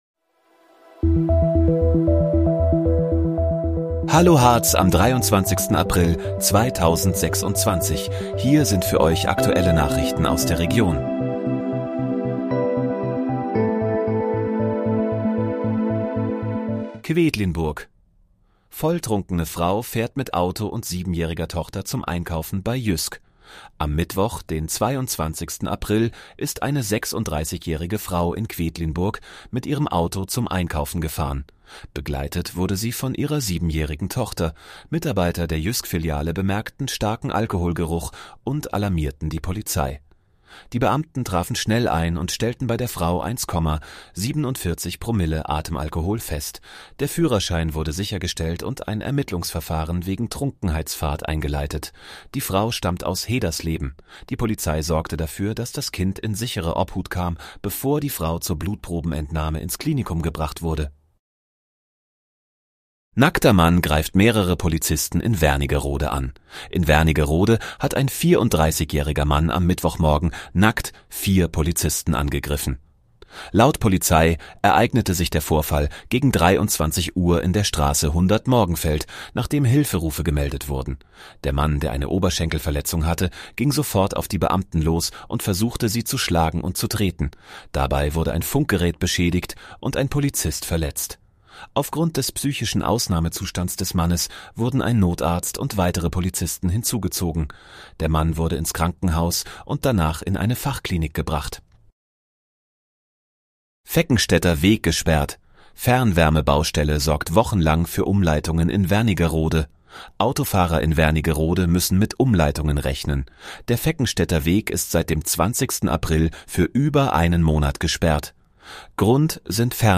Hallo, Harz: Aktuelle Nachrichten vom 23.04.2026, erstellt mit KI-Unterstützung